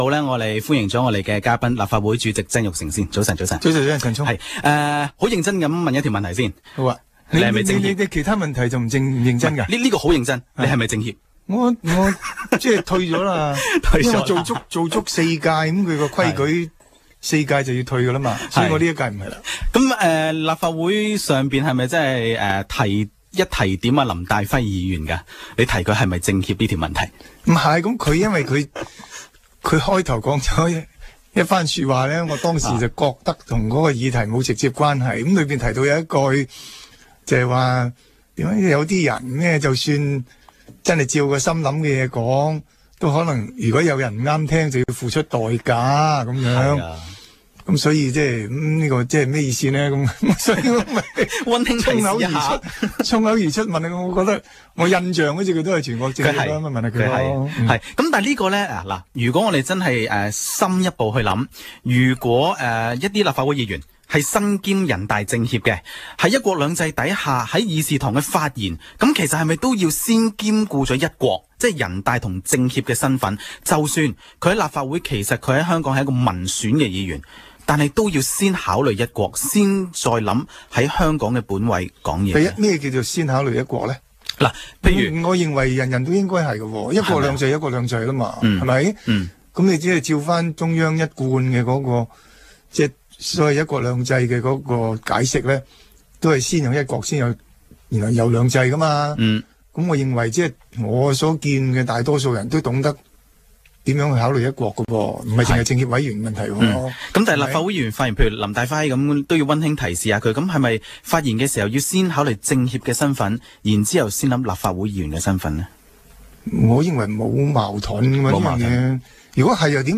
商業電台《不平平則鳴》訪問